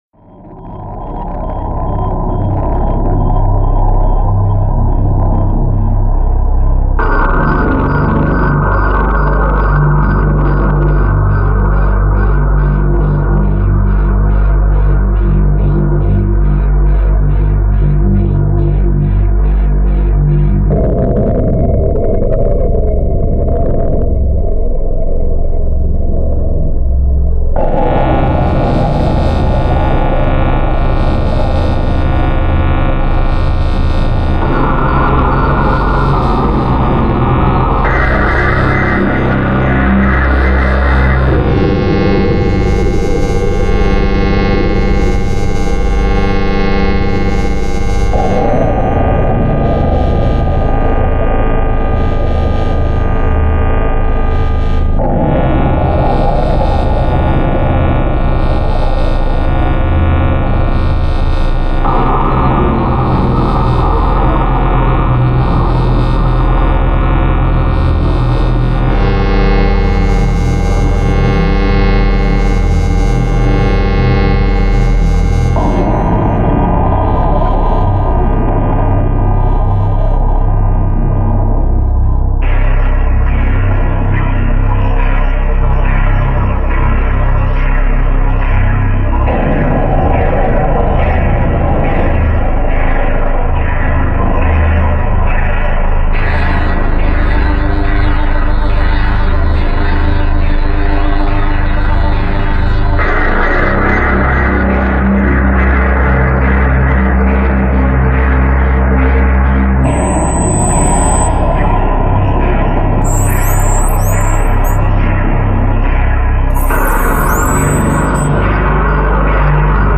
Dark Ambient & Death Industrial